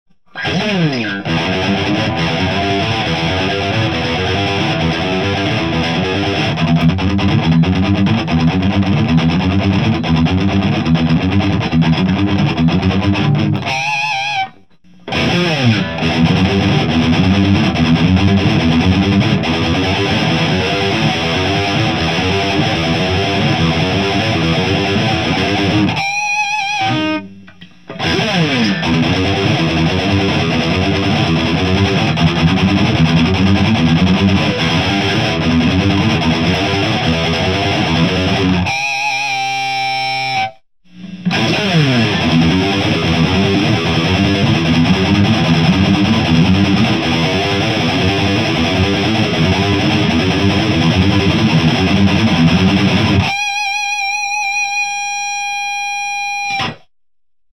ギターはFERNANDES MG-120Xです。
MTRはMRS-8を使いました。マイクはSM57 PG57
いつものセッティングで撮ってみました。ブースターとしてPA-2も使っています。
JCM2000 DSL100
ULTRA GAIN
GAIN5 Bass8 Middle10 Treble7